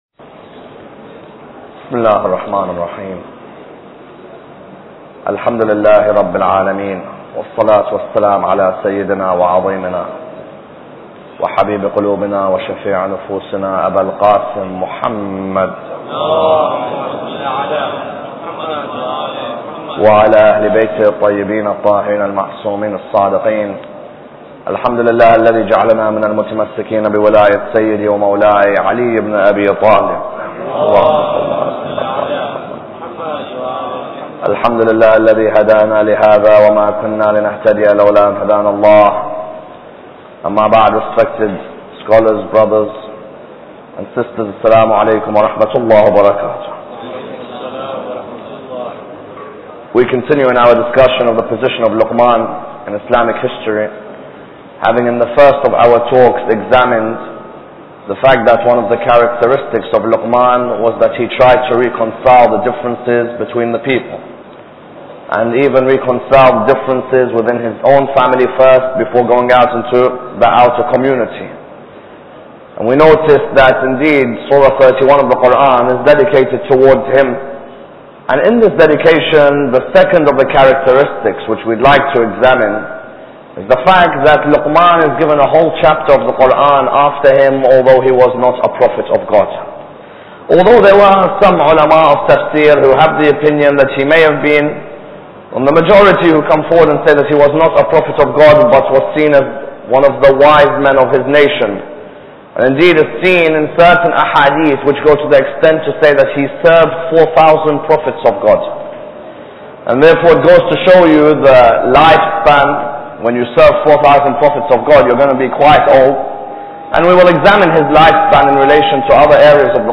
Lecture 2